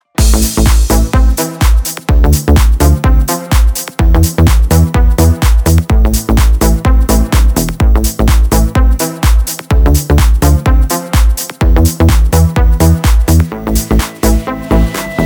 • Качество: 320, Stereo
ритмичные
электронная музыка
без слов
house
Жанры: Хаус